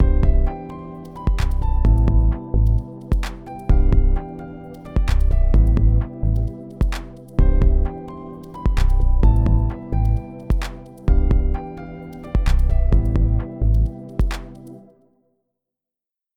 C major chord with C major scale:
C-major-on-C-major-Song.mp3